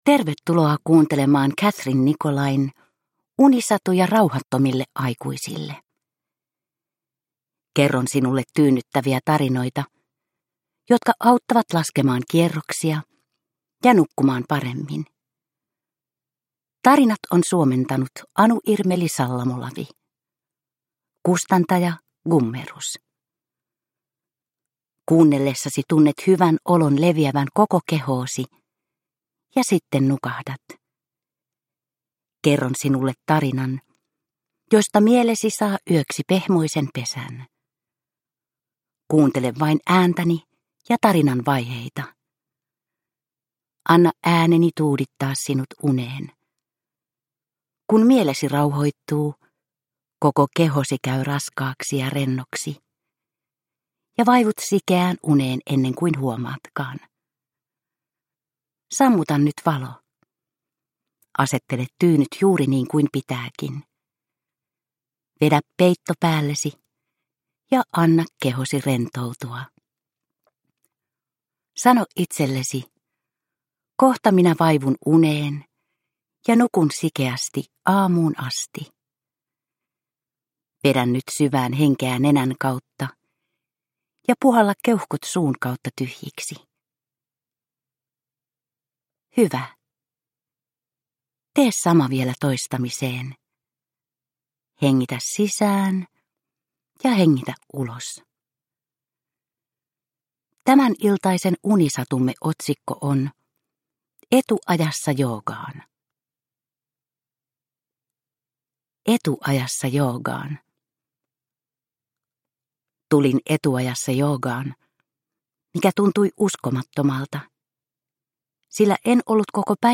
Unisatuja rauhattomille aikuisille 27 - Etuajassa joogaan – Ljudbok – Laddas ner